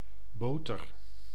Ääntäminen
Ääntäminen RP : IPA : /ˈbʌ.tə/ UK : IPA : [ˈbʌt.ə] US : IPA : [ˈbʌɾ.ə] Tuntematon aksentti: IPA : /ˈbʌ.təɹ/ US : IPA : /ˈbʌ.tɚ/ IPA : [ˈbʌɾɚ] Northern and Midland England, Wales, Scotland: IPA : /ˈbʊ.tə/